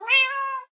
sounds / mob / cat / meow3.ogg
meow3.ogg